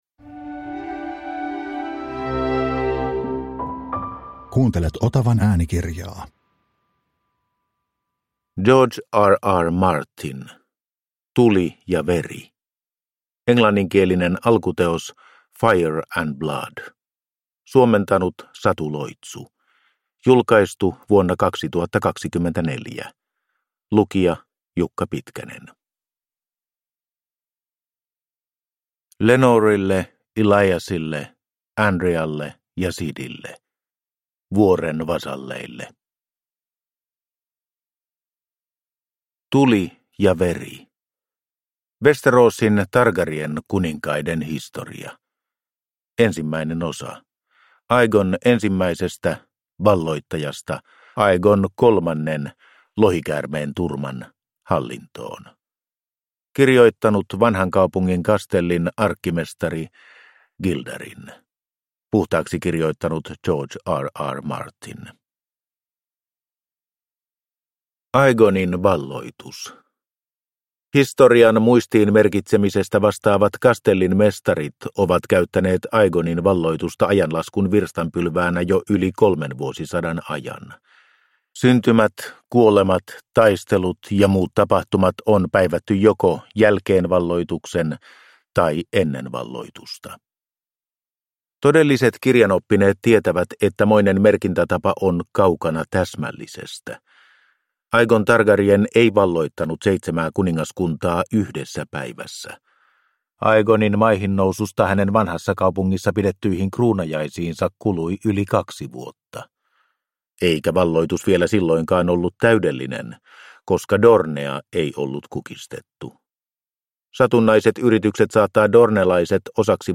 Tuli & veri – Ljudbok